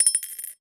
Coin Dropped on Ceramic Dish.wav